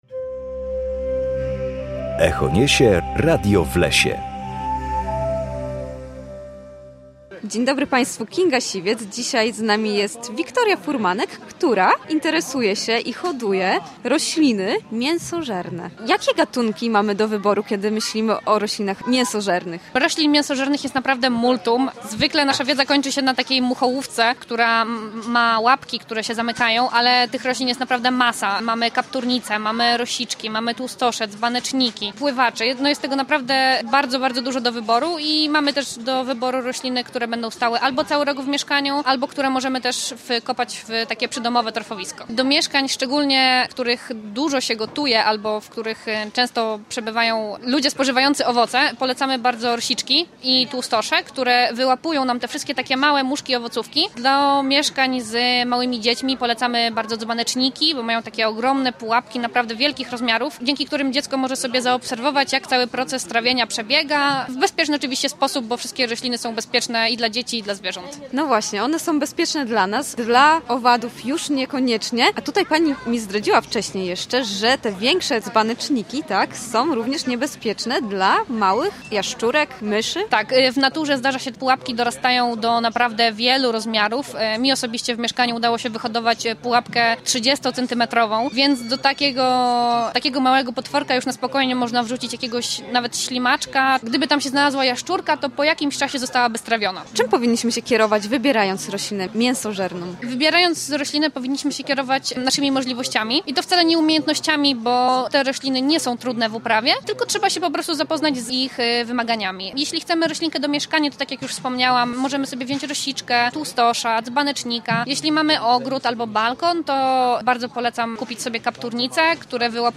W każdą środę o godzinie 7:20 na antenie Studia Słupsk rozmawiamy o naturze i sprawach z nią związanych.